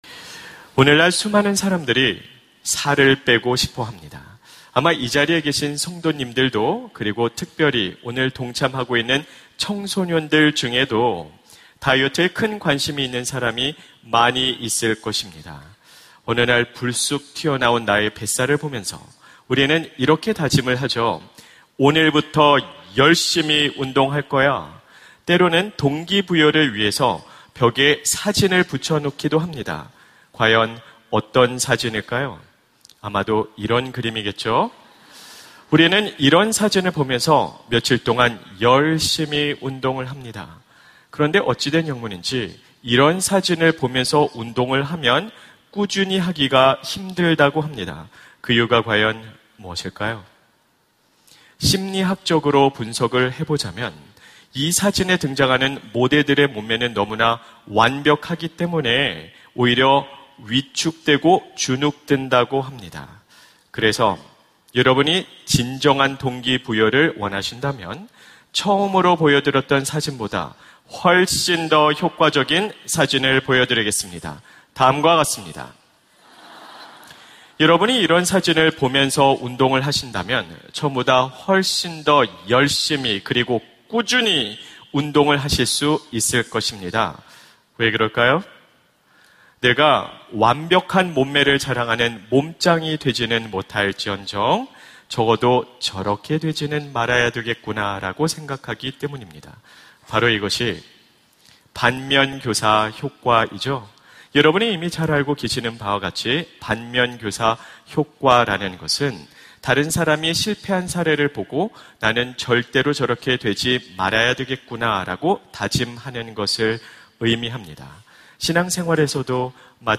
설교 : 주일예배 인생 망치는 법을 가르쳐드립니다! 설교본문 : 마태복음 25:14-30